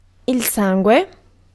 Ääntäminen
IPA : /blʌd/ US